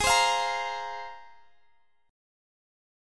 Listen to G#6add9 strummed